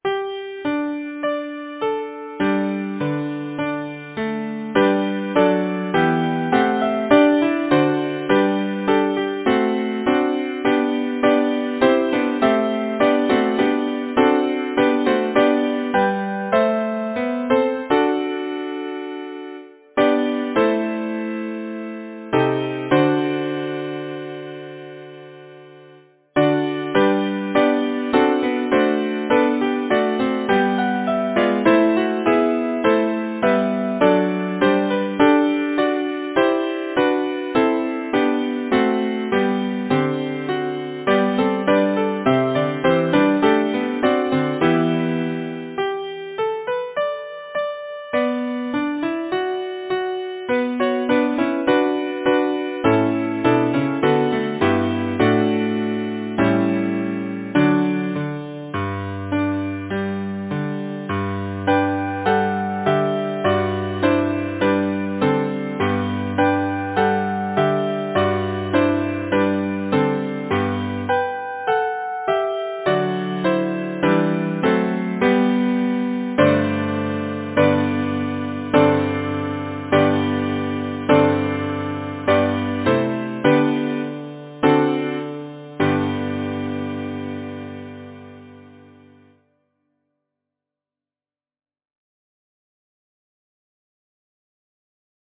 Title: Tell me where is fancy bred Composer: Frederick St. John Lacy Lyricist: William Shakespeare Number of voices: 4vv Voicing: SATB Genre: Secular, Partsong
Language: English Instruments: A cappella